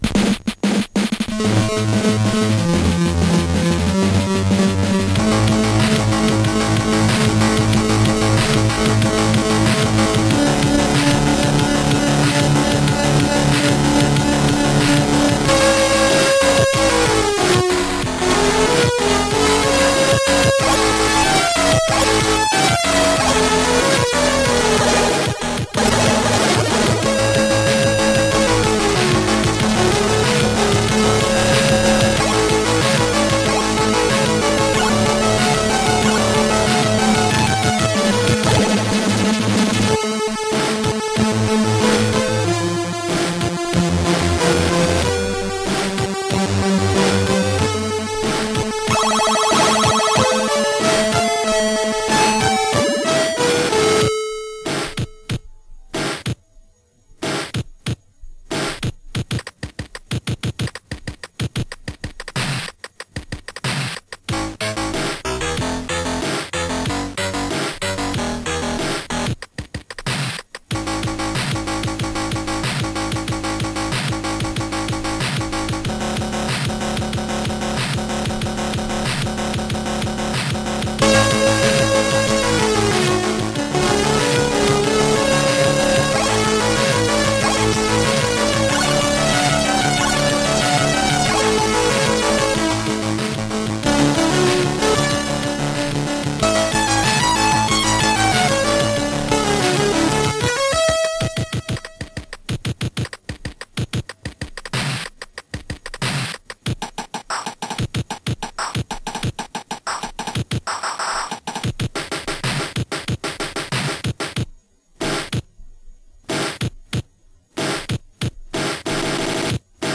Samples and a few musical citations